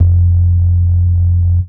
04 Vrrt F#.wav